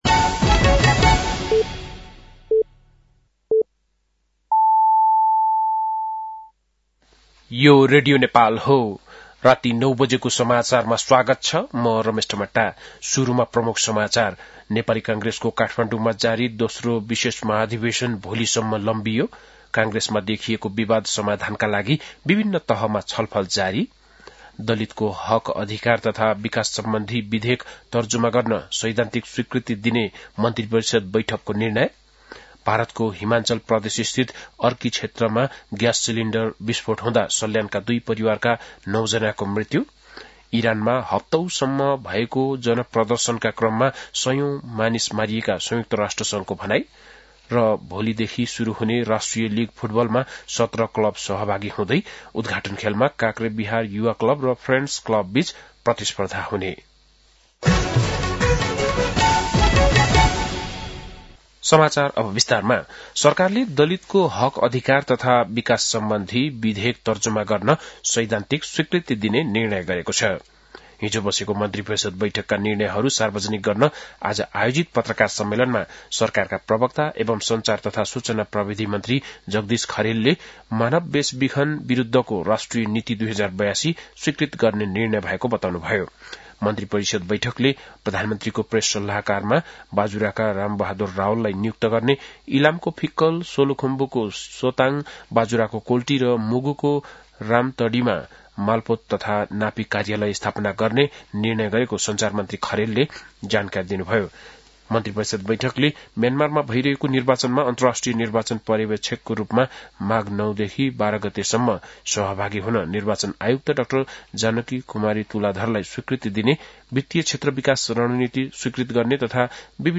बेलुकी ९ बजेको नेपाली समाचार : २९ पुष , २०८२
9-pm-nepali-news-9-29.mp3